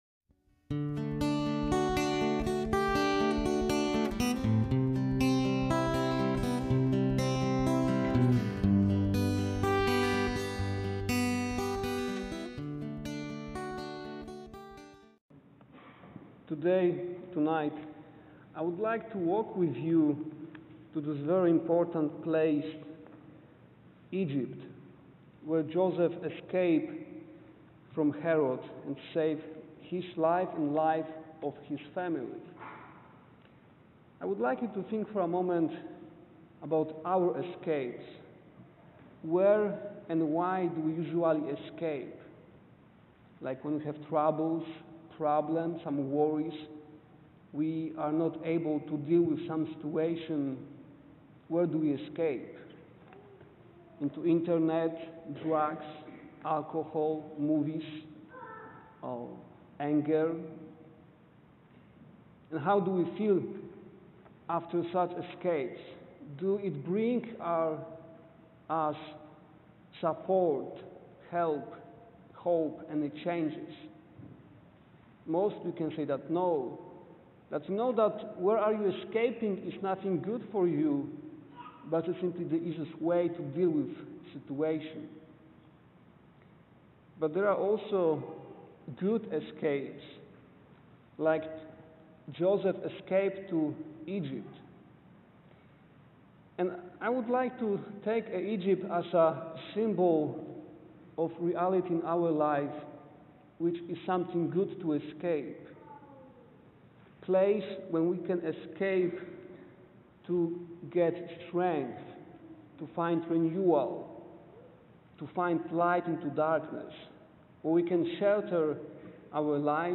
Homily for the Feast of the Holy Family